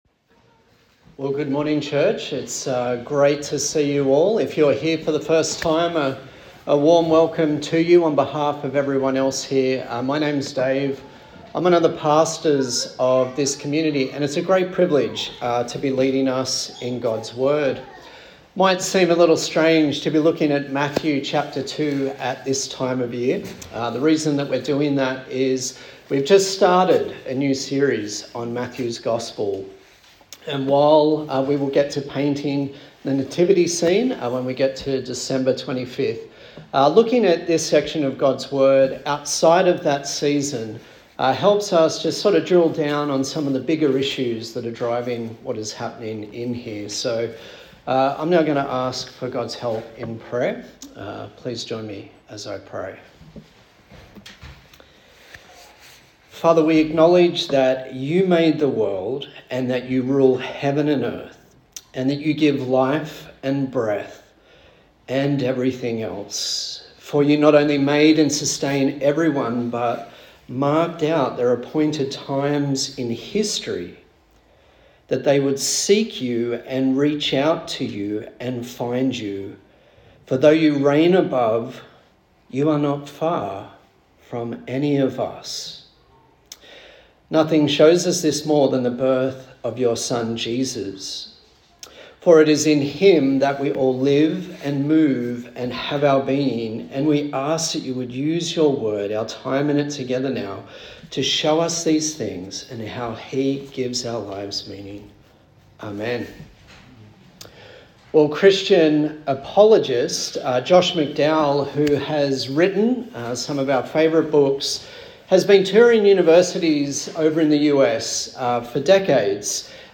Passage: Matthew 2:1-23 Service Type: Sunday Morning